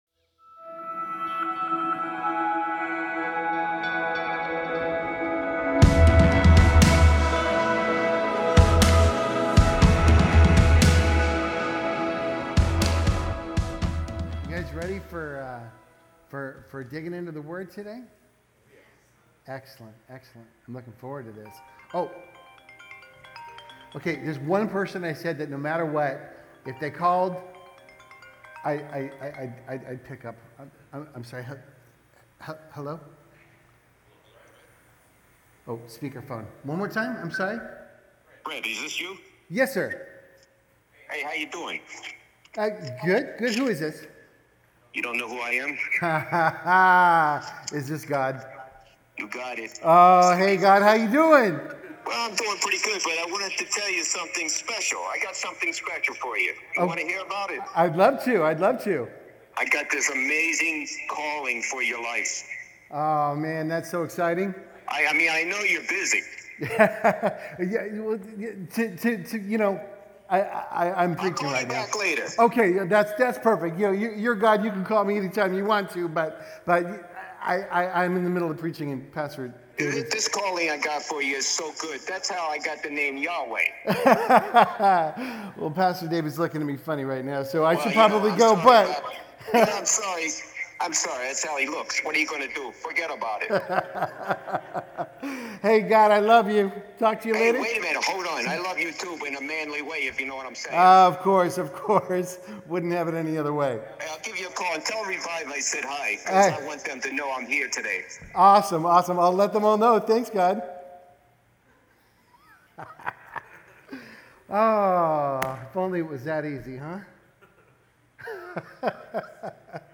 From Series: "Topical Sermons"